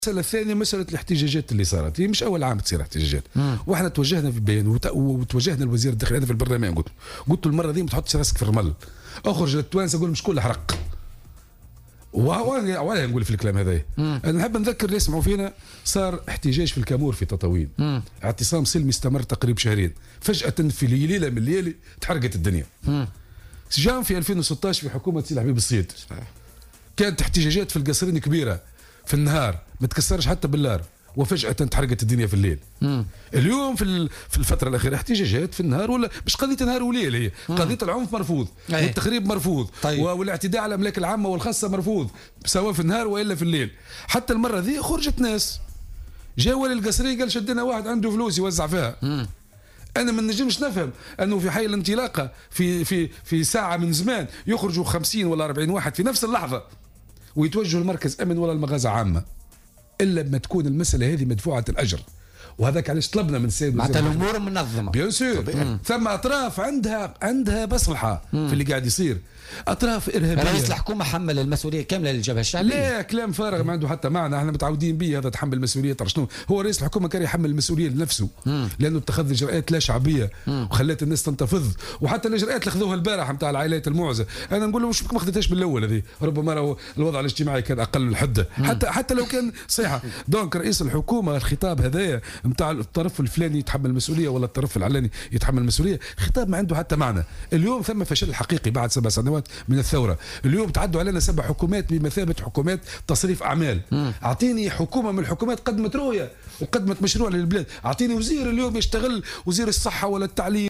وأضاف المغزاوي، ضيف برنامج "بوليتيكا" اليوم أن أعمال الشغب والتخريب "مدفوعة الأجر"، مشيرا إلى أن هناك أطرافا لها مصلحة فيما يحدث، حسب قوله.